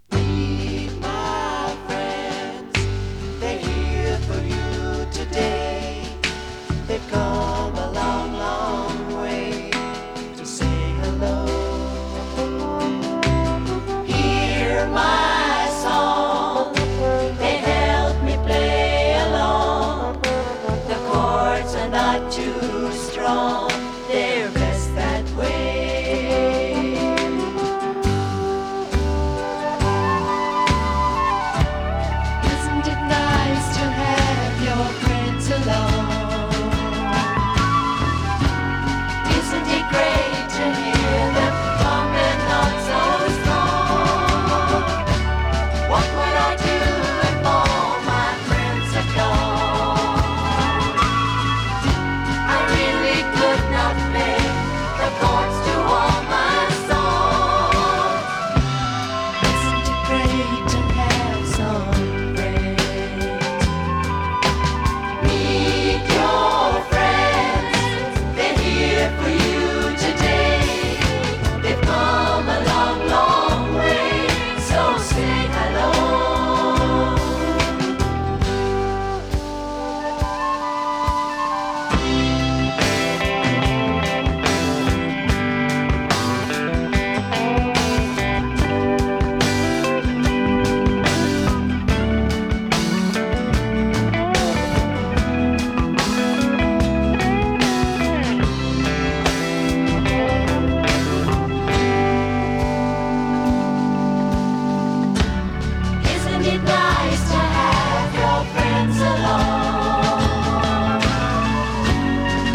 UKの男女混声6人組グループのサードLP。
美しいコーラスにドラマチックでヒネったアレンジが素晴らしい英国ポップです！